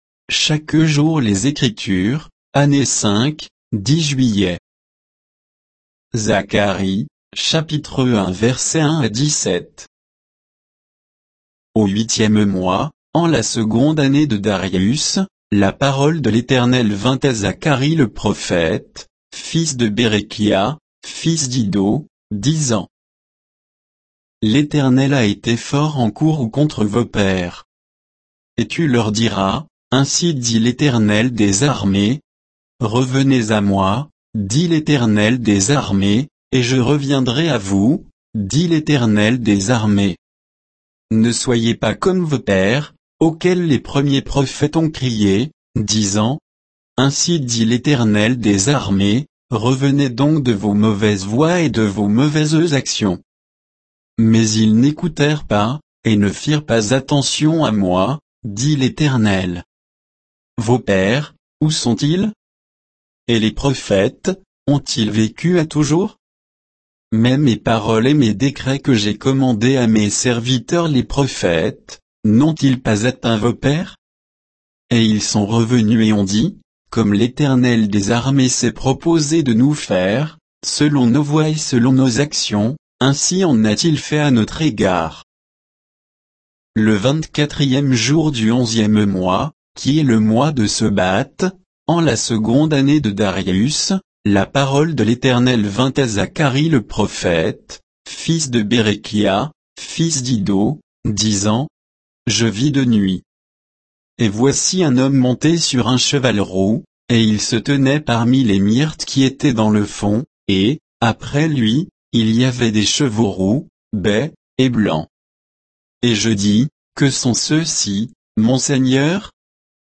Méditation quoditienne de Chaque jour les Écritures sur Zacharie 1, 1 à 17